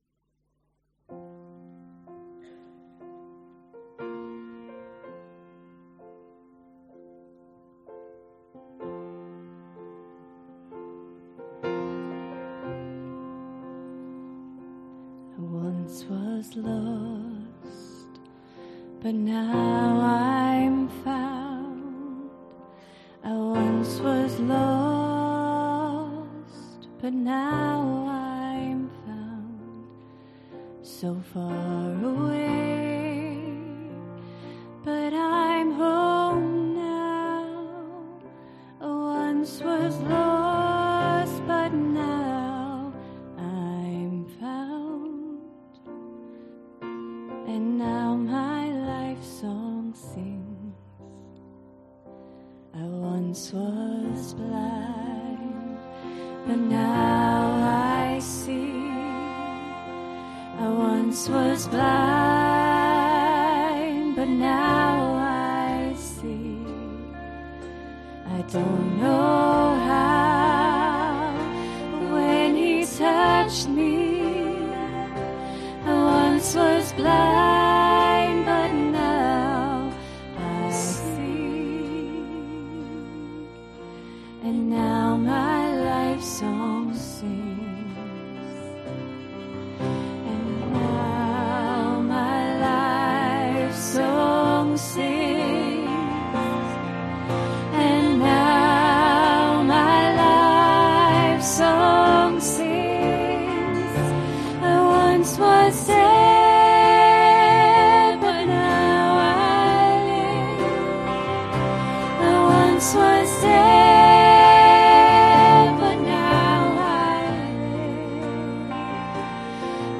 Ministry Song http